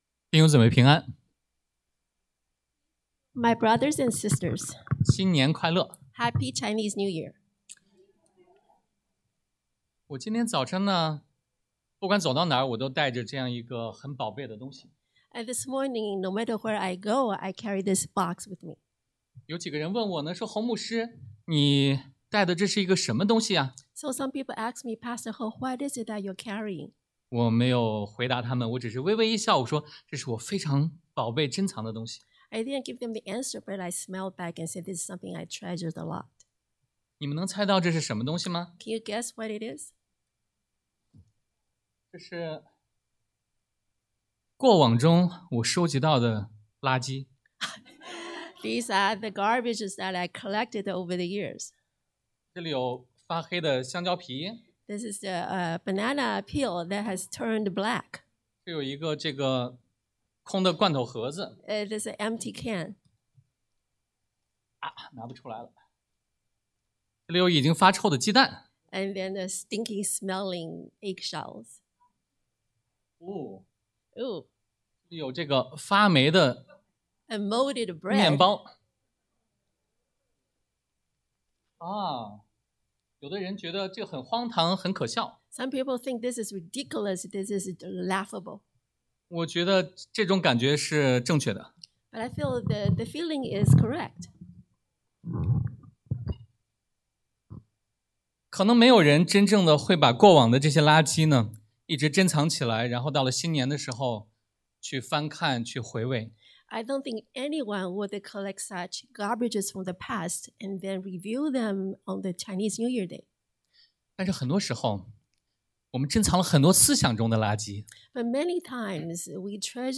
Passage: 腓立比书 Philippians 3:10-14 Service Type: Sunday AM 忘记背后 Forgetting the Past 努力面前 Pressing Forward 向着标竿 Pursuing the Goal